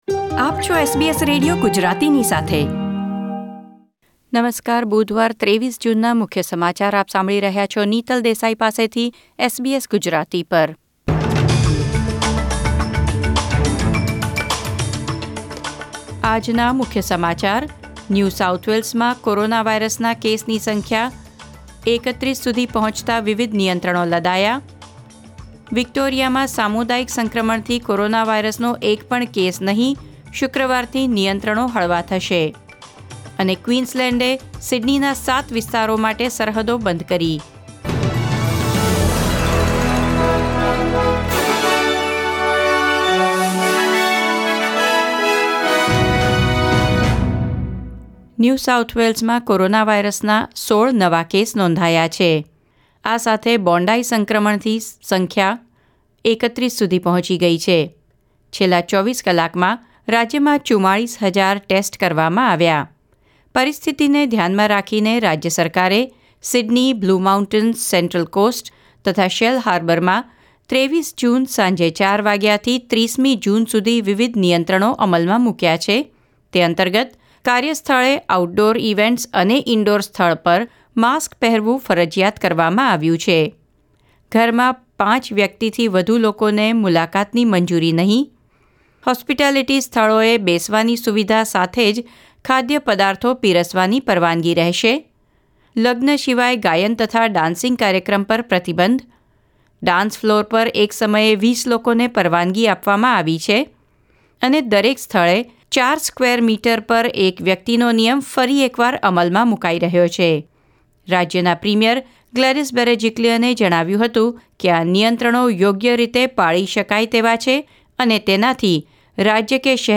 SBS Gujarati News Bulletin 23 June 2021